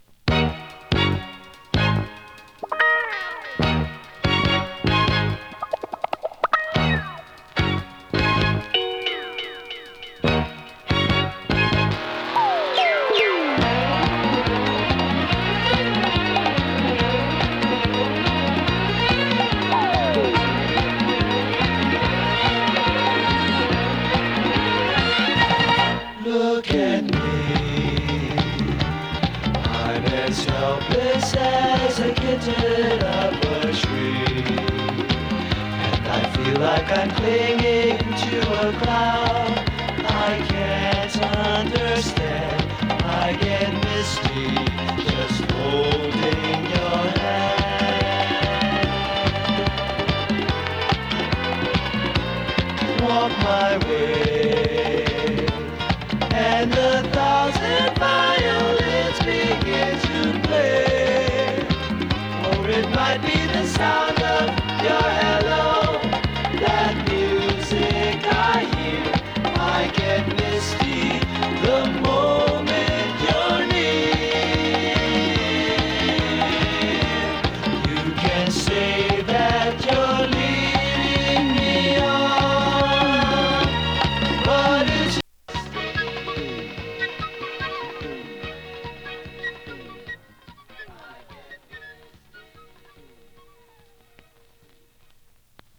R&B、ソウル
音の薄い部分で時折軽いパチ・ノイズ。